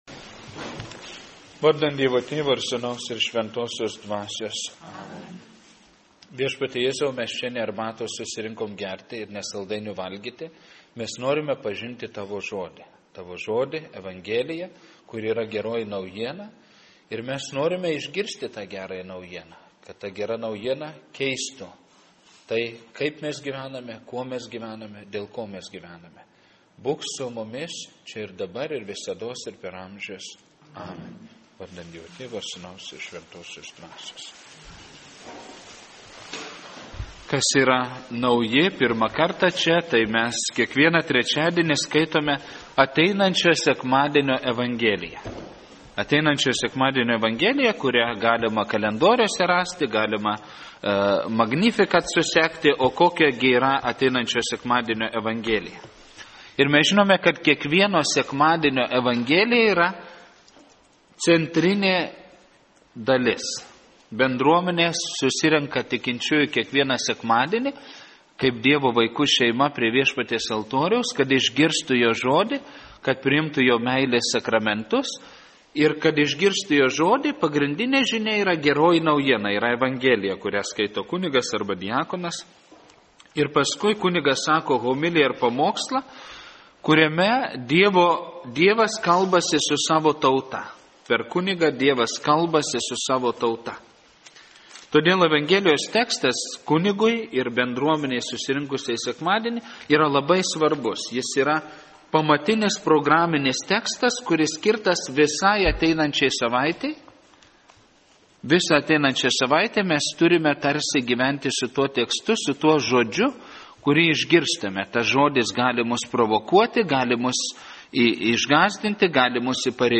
Paskaitos audioįrašas